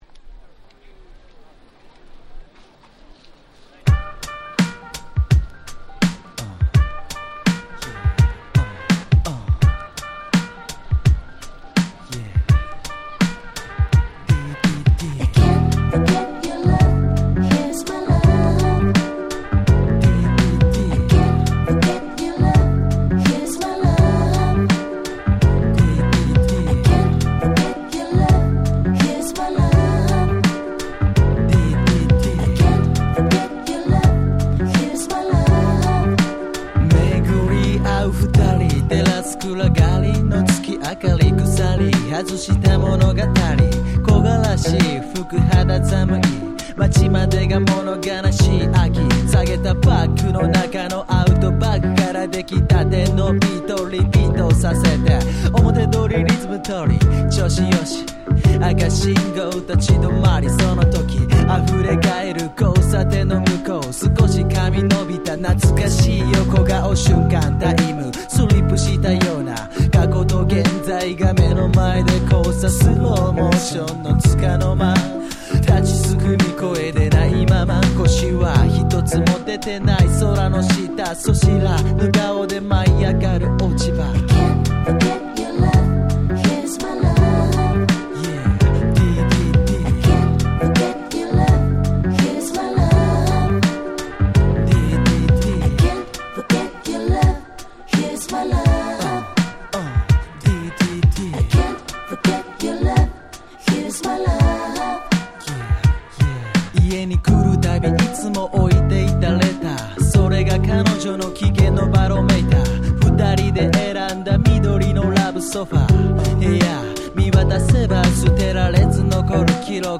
96' Very Nice Japanese Hip Hop !!
当時の日本語ラップの主流は「ハーコーでリアルでドープ」でしたが、コチラは甘〜いラブソング。
90's J-Rap